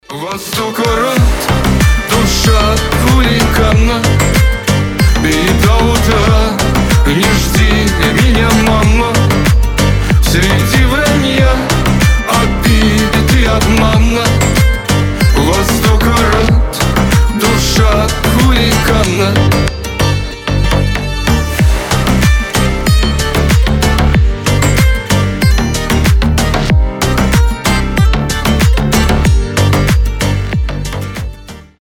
шансон , поп